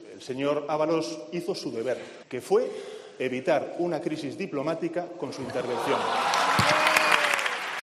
Sánchez ha hecho esta alusión a Guaidó en la primera sesión de control al Gobierno de la legislatura en el Congreso en respuesta a una pregunta del presidente de Vox, Santiago Abascal, sobre si Ábalos se reunió con la vicepresidenta venezolana siguiendo "instrucciones expresas" de Sánchez.